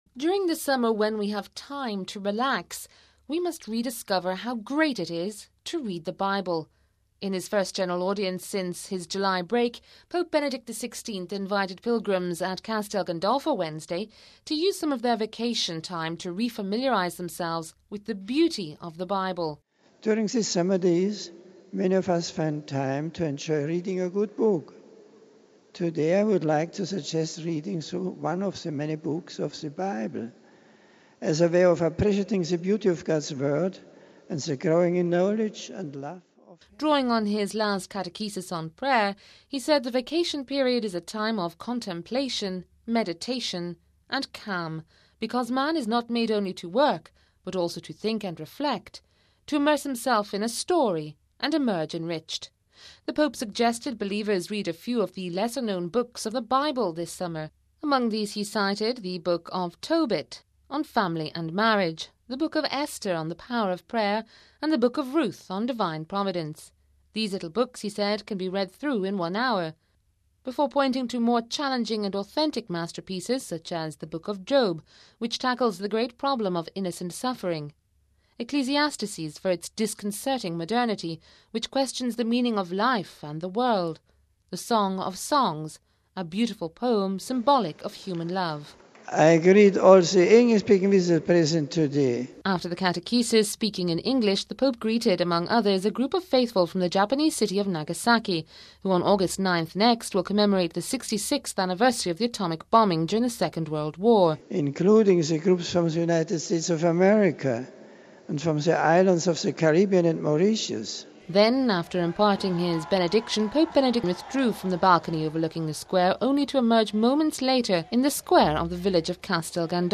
In the first general audience since his July break, Pope Benedict XVI invited pilgrims at Castel Gandolfo, to use some of their vacation to re-familiarise themselves with the beauty of the bible, and meditate on its meaning.
Thousands flooded the main square of the tiny hill top village which borders onto the main entrance to the Papal summer residence.